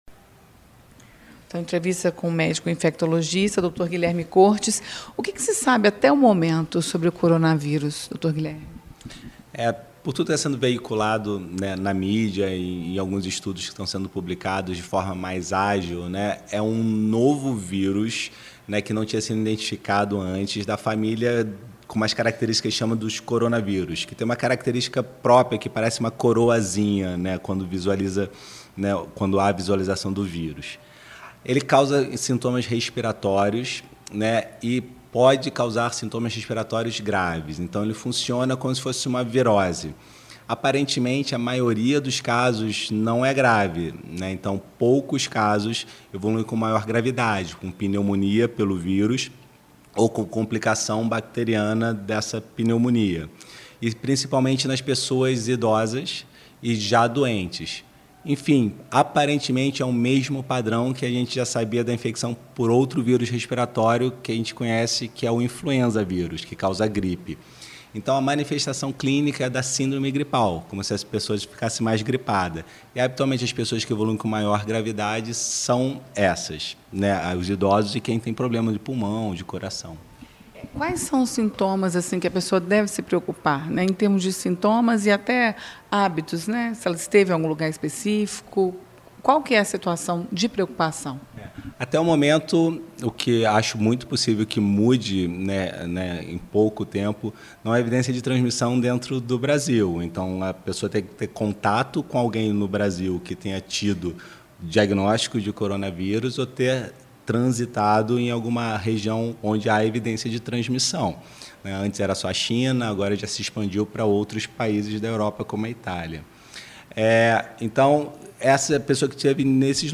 Infectologista fala sobre o coronavírus - Rádio FM Itatiaia